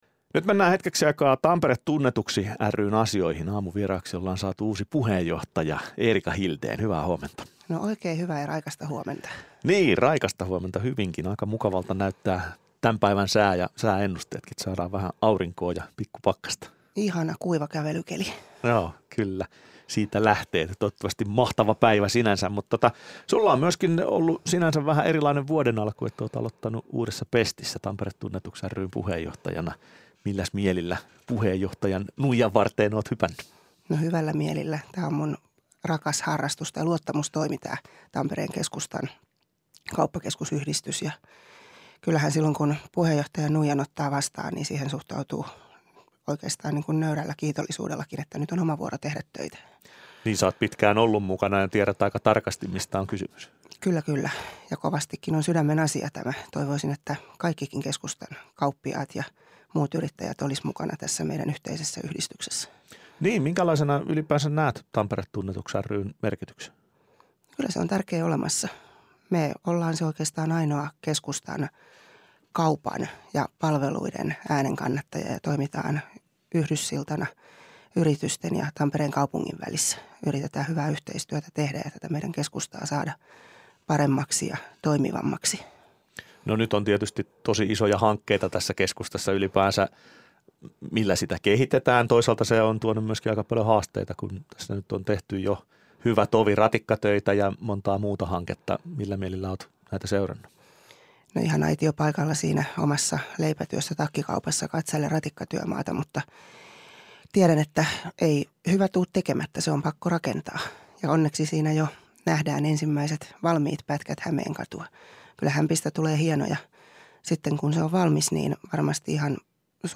Päivän vieras